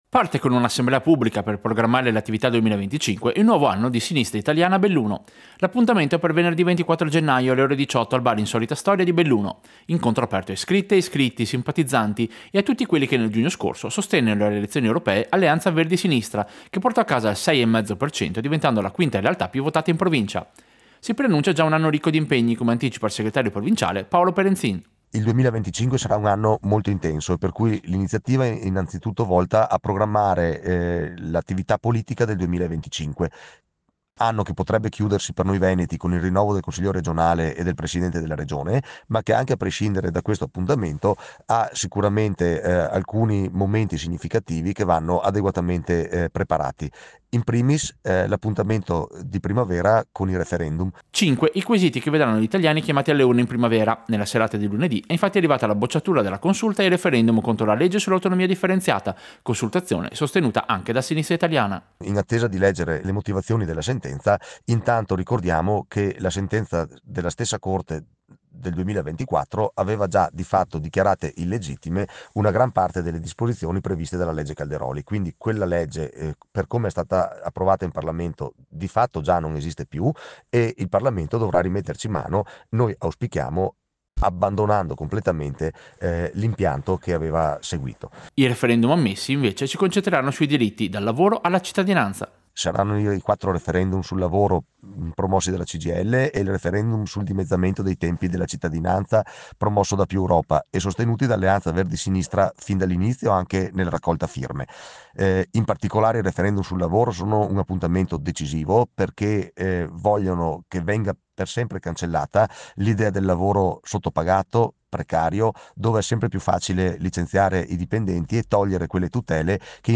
Servizio-Sinistra-Italiana-2025.mp3